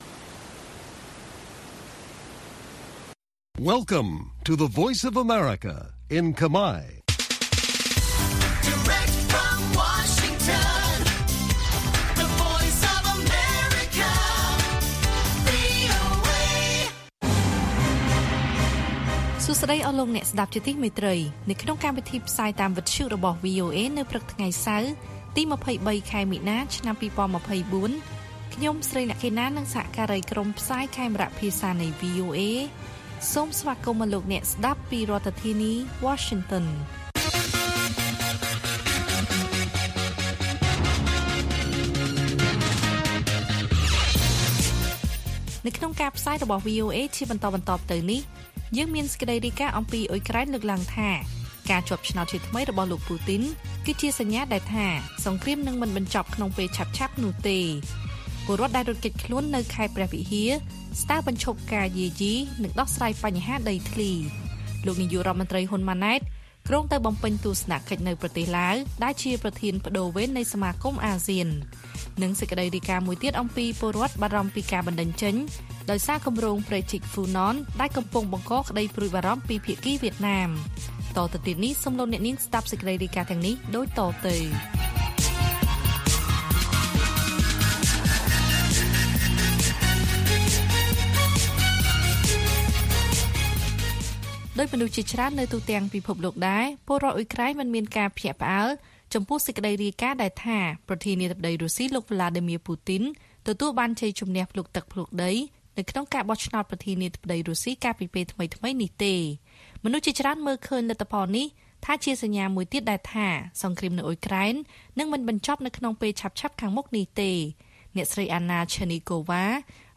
ព័ត៌មានពេលព្រឹក ២៣ មីនា៖ ពលរដ្ឋដែលរត់គេចខ្លួននៅខេត្តព្រះវិហារស្នើបញ្ឈប់ការយាយីនិងដោះស្រាយបញ្ហាដីធ្លី